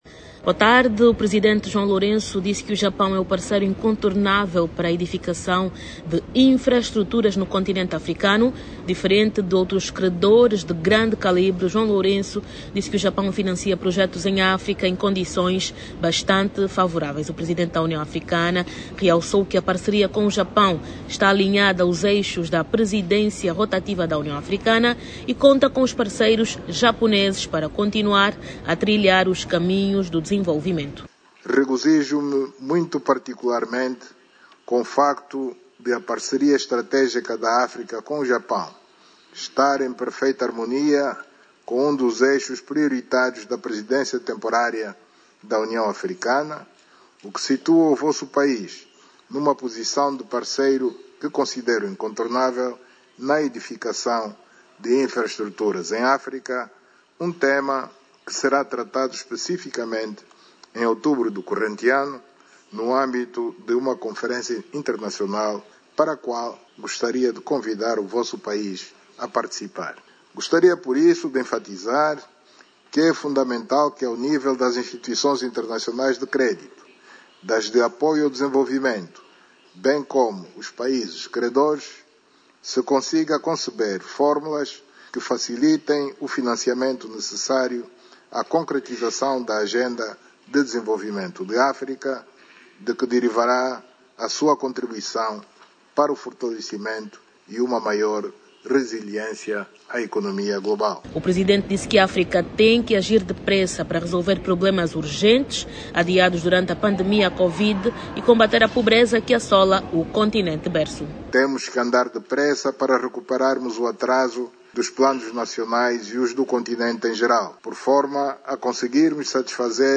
a partir de Yokohama, no Japão